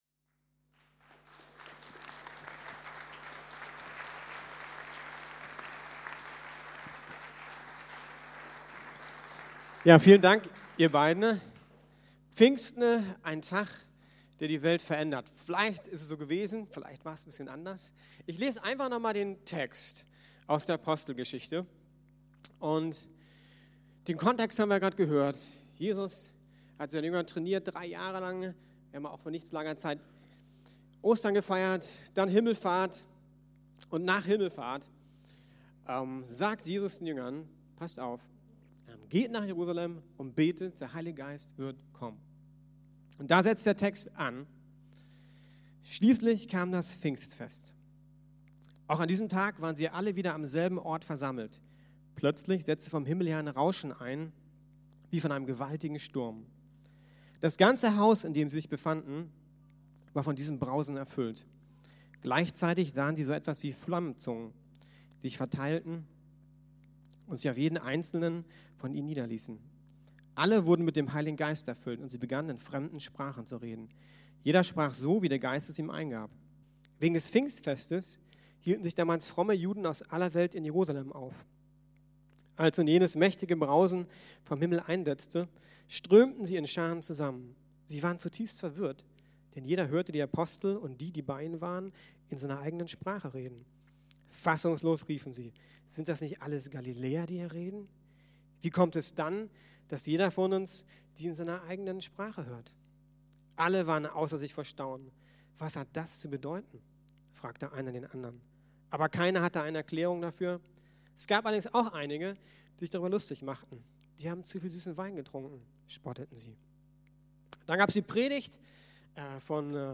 Ein Tag, der die Weltgeschichte verändert ~ Predigten der LUKAS GEMEINDE Podcast
Pfingsten für dich ganz praktisch. Mit Gebet am Ende.